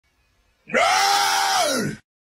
Goofy Violent Scream Aaaaarg! - Botón de Efecto Sonoro